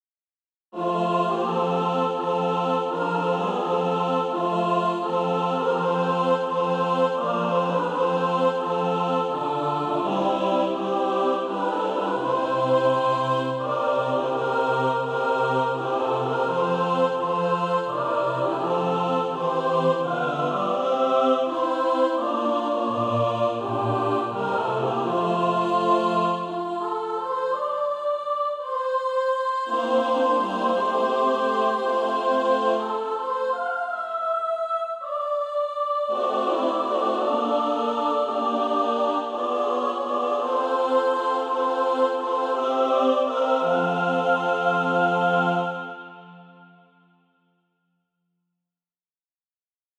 (SATB) Author
And also a mixed track to practice to
Practice then with the Chord quietly in the background.